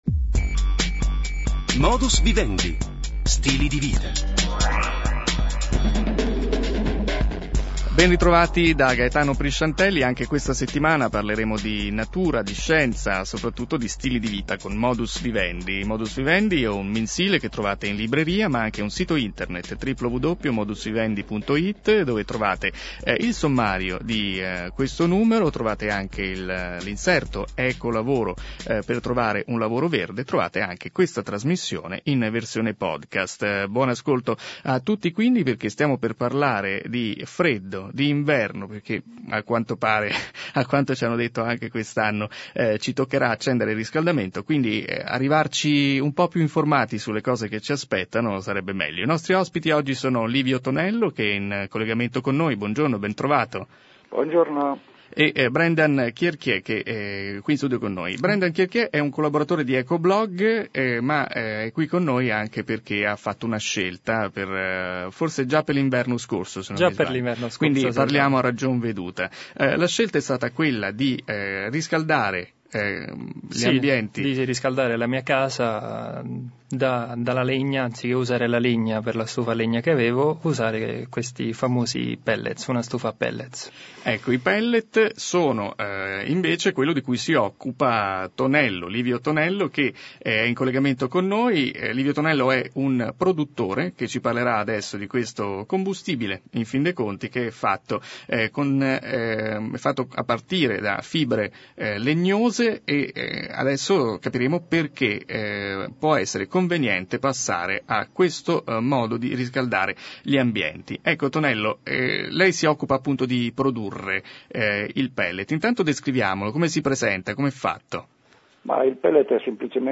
Quasi interessante nonostante tutto, mamma mia quante papere....